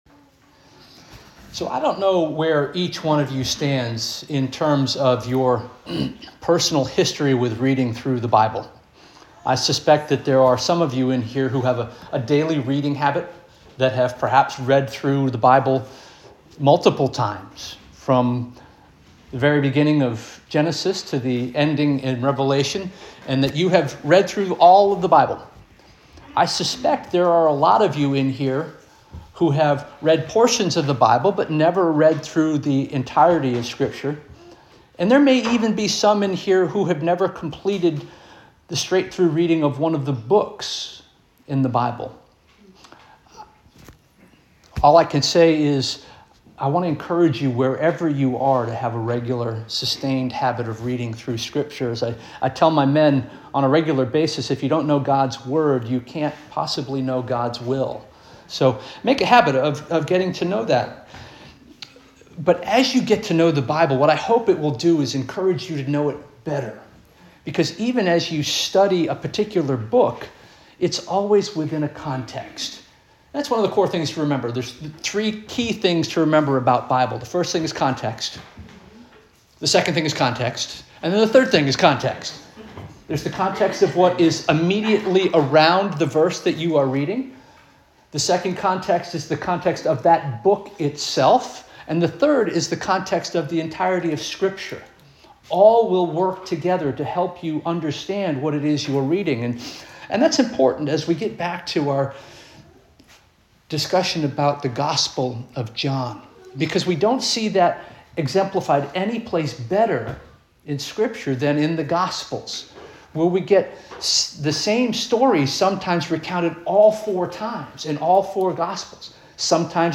January 11 2026 Sermon - First Union African Baptist Church